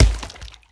stone_place.ogg